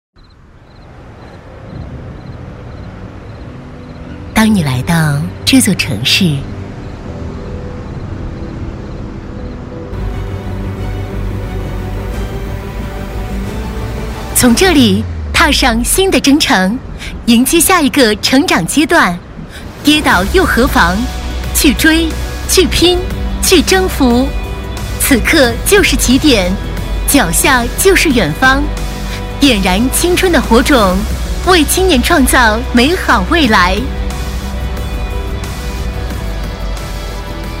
女108-城市宣传-走心 激情（情感渐起）
女108-城市宣传-走心 激情（情感渐起）.mp3